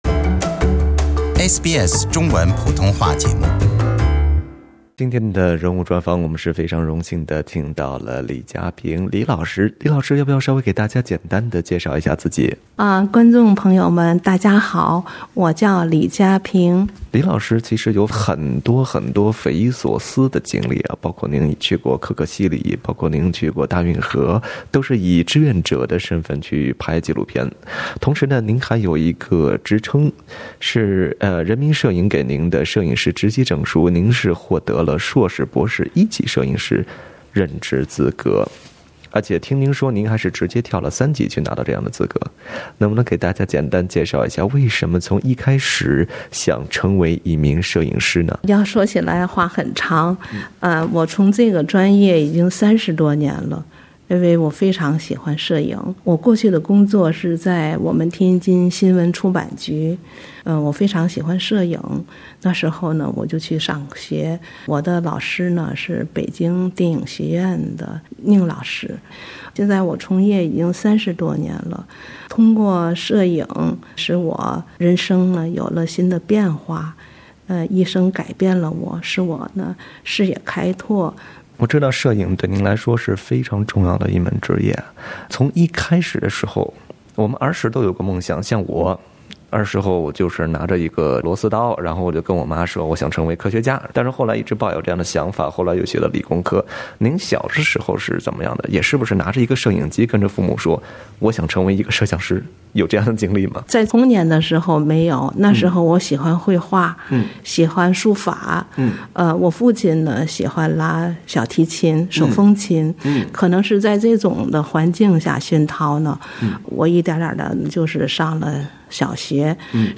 【专访】行走三十年